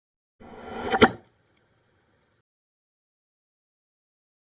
Poltergeist Burp Sound Button - Free Download & Play